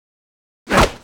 挥动击中zth070510.wav
通用动作/01人物/03武术动作类/挥动击中zth070510.wav
• 声道 單聲道 (1ch)